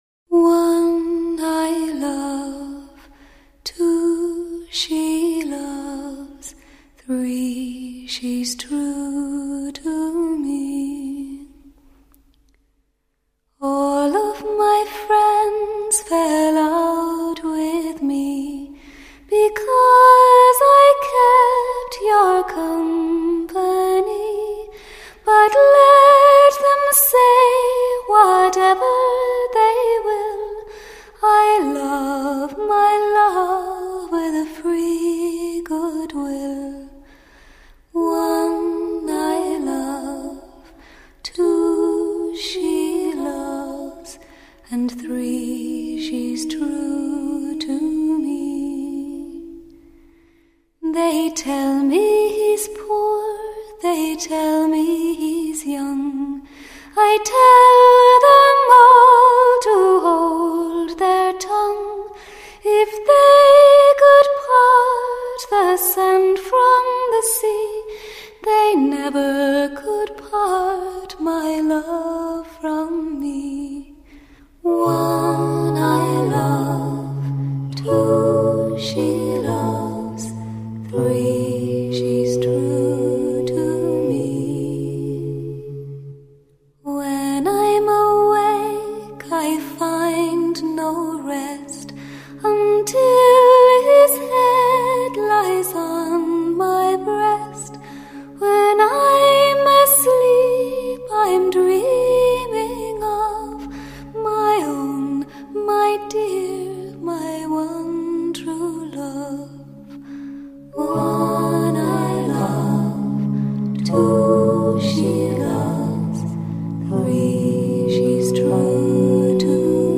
Folk / Celtic
Oboe
简单的伴奏乐器
唱片在主唱的声音中加入适当的混响获得一种辽阔的空间感，给人一种飘逸的意境。